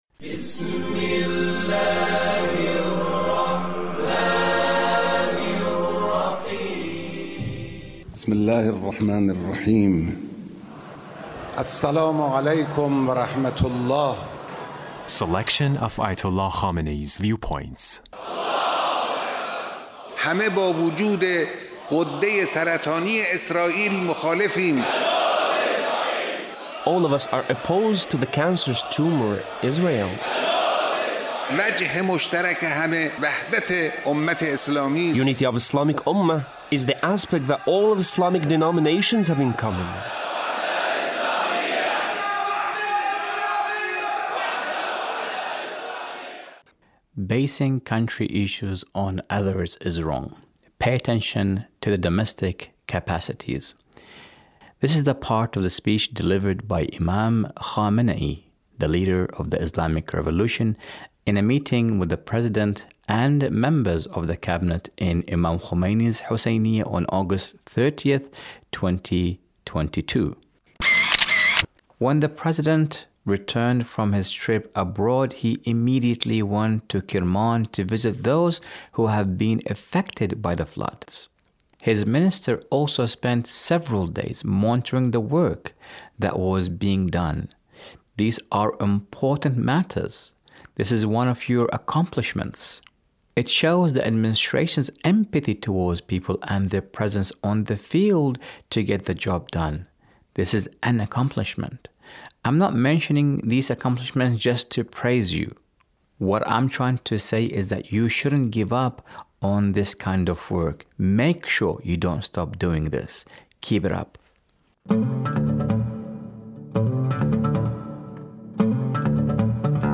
Leader's Speech on a Gathering with Friday Prayer Leaders